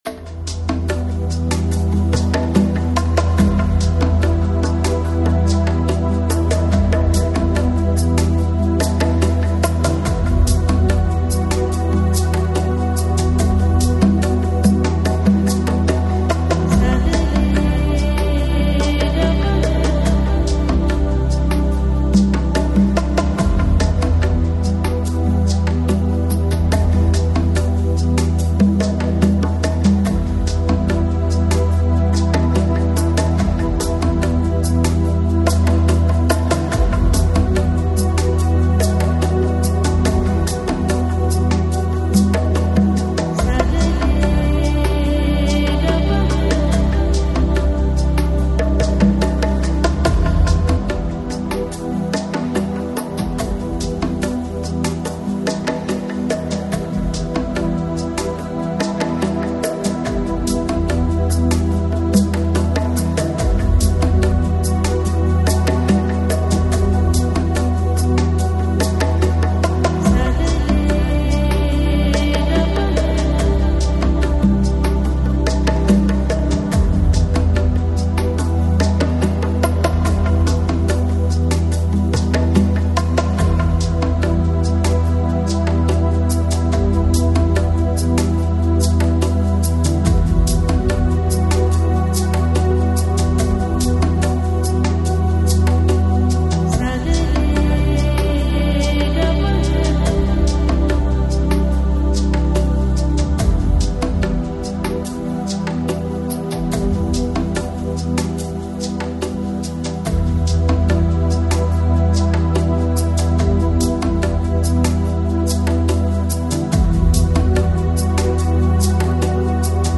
AAC Жанр: Lounge, Chill Out, Downtempo Продолжительность